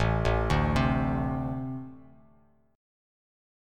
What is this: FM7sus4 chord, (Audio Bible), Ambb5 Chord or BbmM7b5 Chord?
Ambb5 Chord